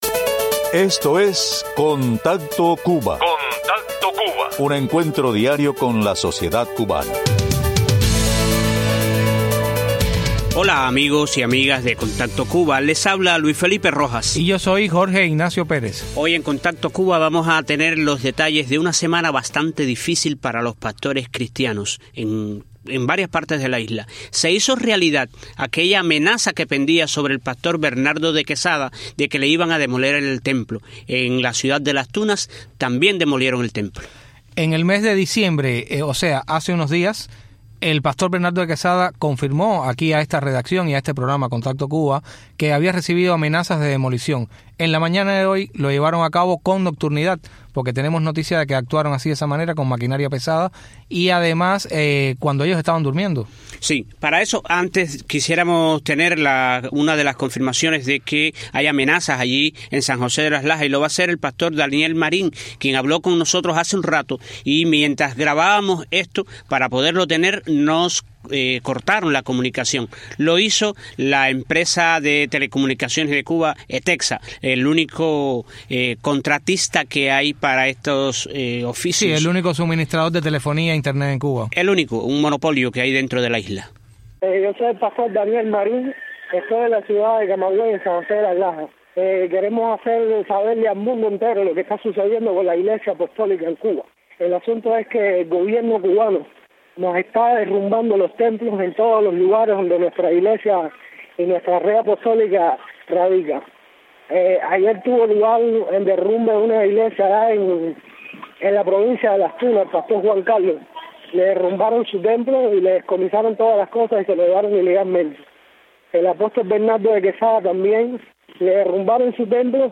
Escuche testimonio de los afectados tras la demolición de casas-templo a pastores cristiano y sus congregaciones por fuerzas combinadas de la policía y la Seguridad del Estado, ocurrido a las 5 de la madrugada en localidades como Camagüey y Las Tunas, y con decenas de detenidos y teléfonos ocupados.